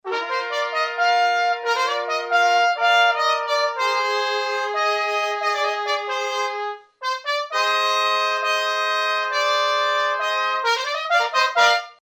Il brano 'Svolta' dei musici di Porta Romana.